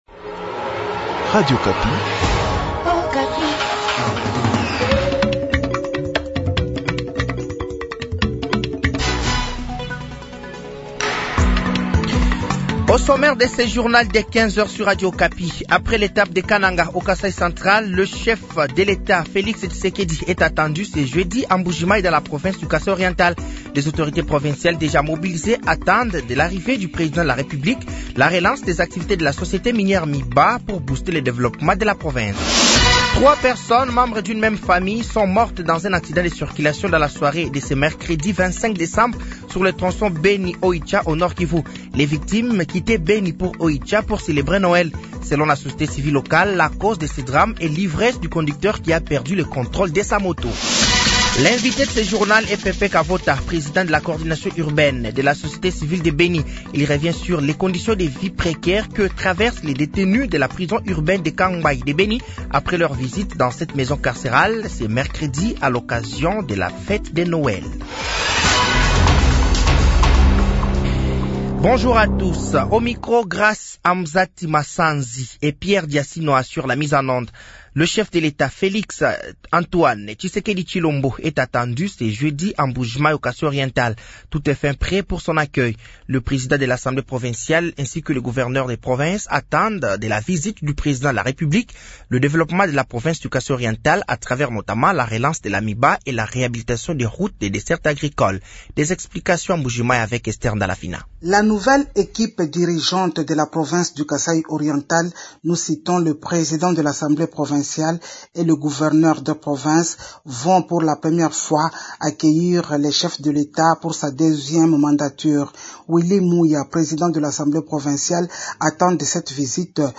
Journal de 15h
Journal français de 15h de ce jeudi 26 décembre 2024